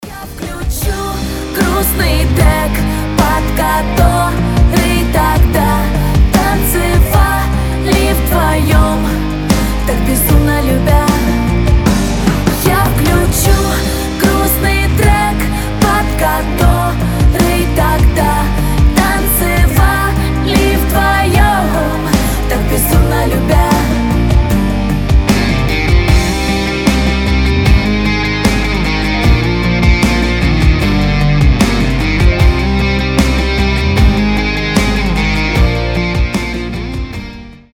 • Качество: 320, Stereo
гитара
грустные
Pop Rock